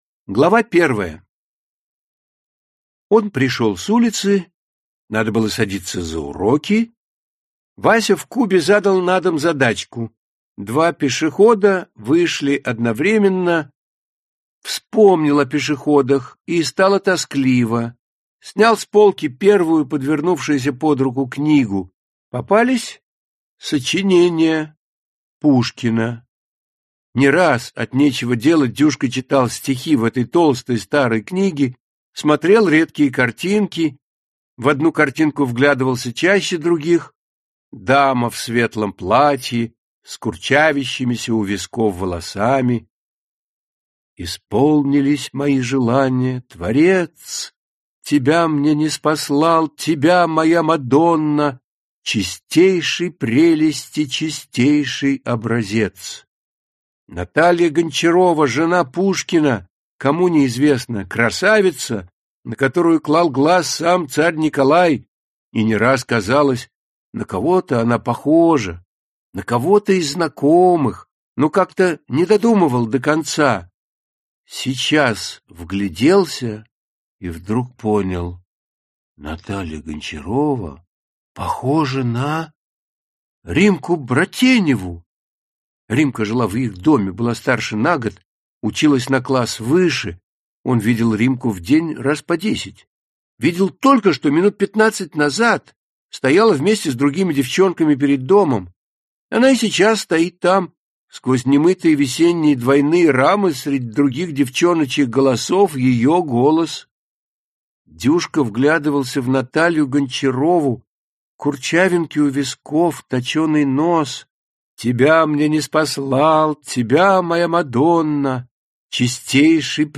Аудиокнига Весенние перевертыши | Библиотека аудиокниг
Aудиокнига Весенние перевертыши Автор Владимир Тендряков Читает аудиокнигу Вениамин Смехов.